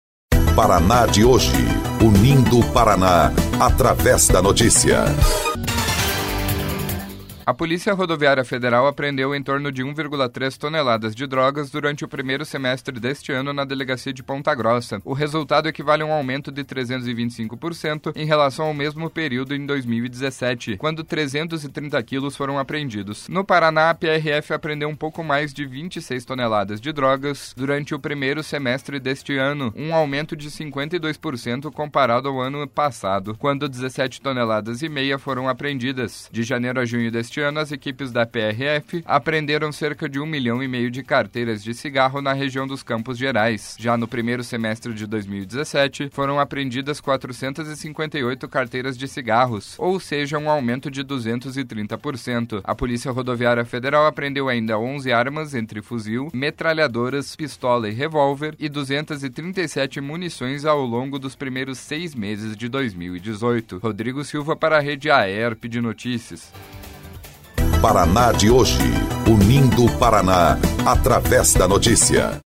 04.07 – BOLETIM – Paraná registra aumento nas apreensões no primeiro semestre de 2018